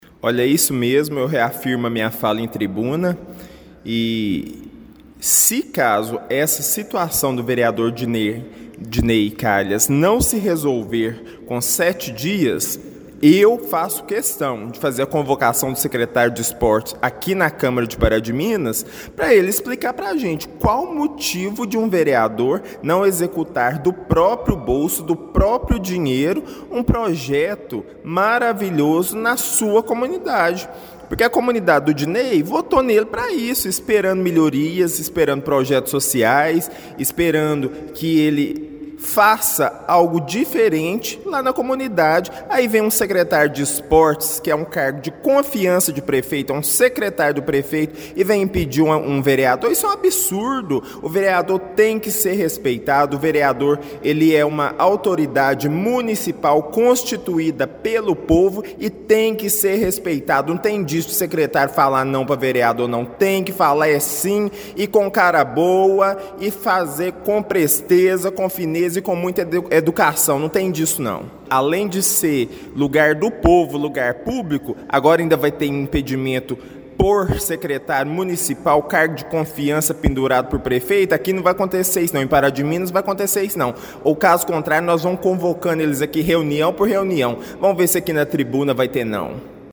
Com dois vereadores cobrando publicamente explicações e ameaçando convocação formal, o clima na Câmara Municipal ficou tenso.